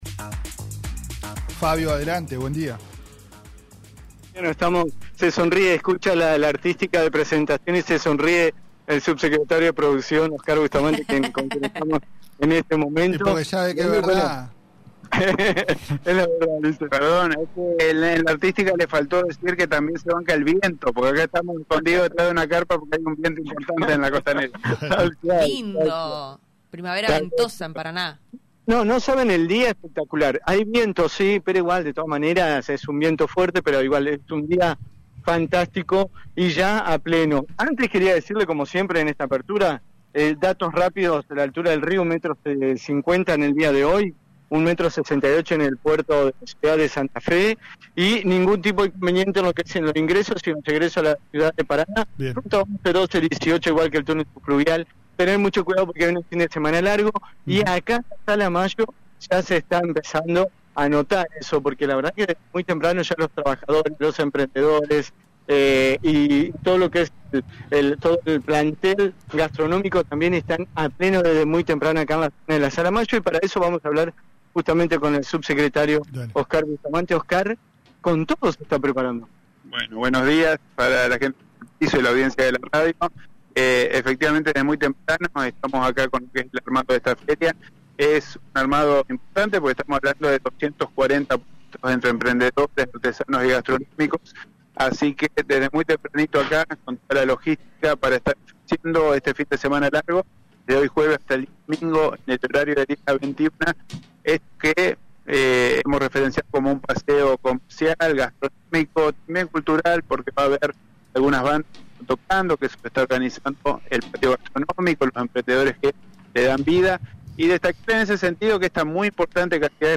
en declaraciones al móvil de Radio Costa Paraná (88.1)